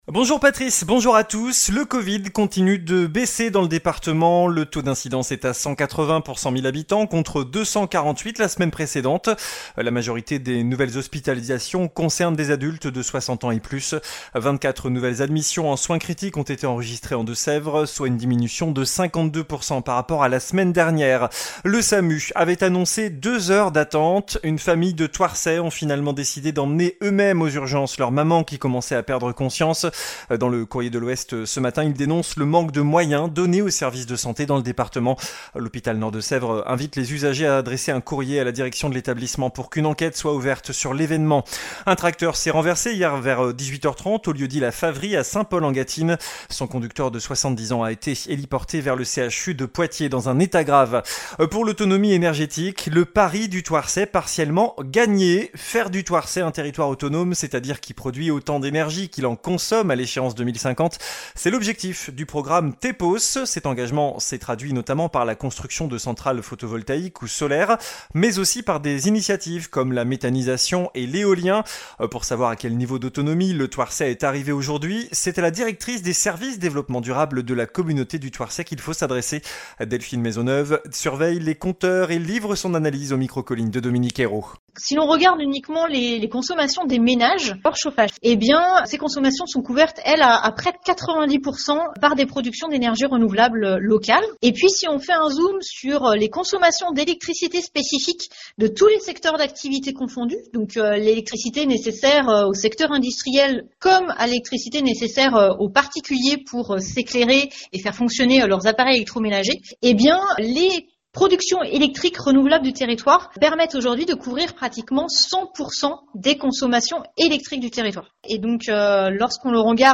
JOURNAL DU SAMEDI 20 AOÛT